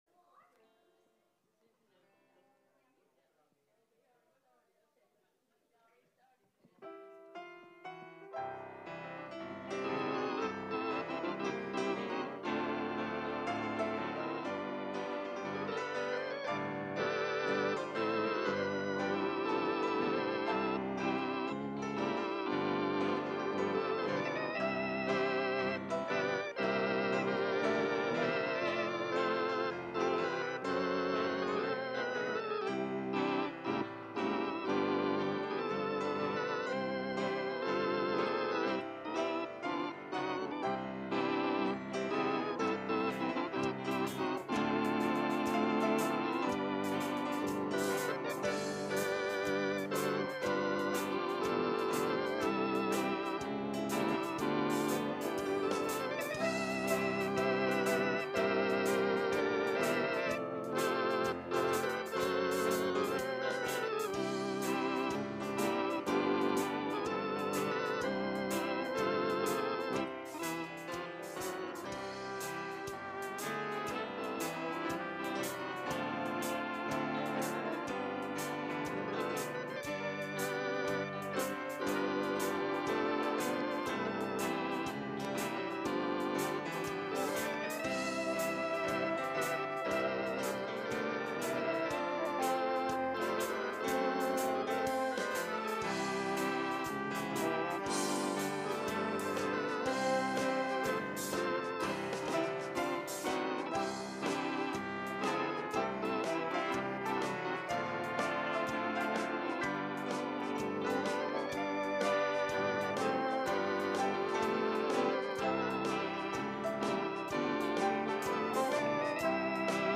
The Mosaic Tabernacle- Teaching Series